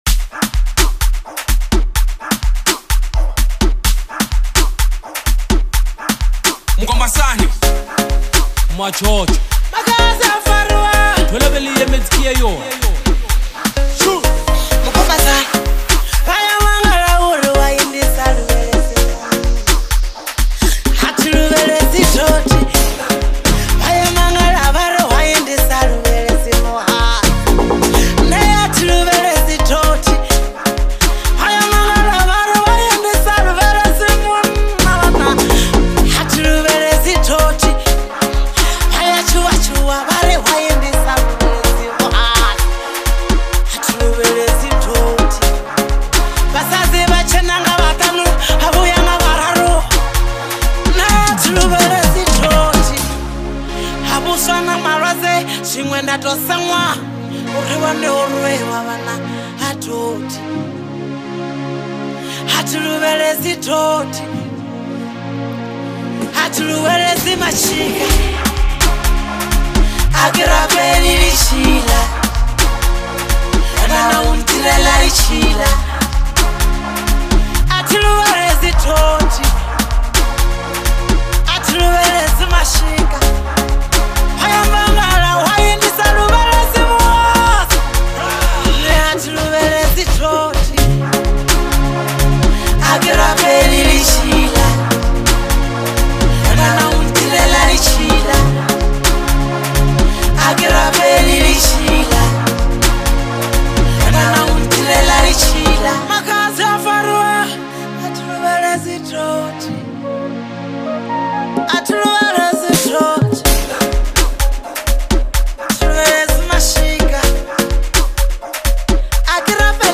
DANCE Apr 07, 2026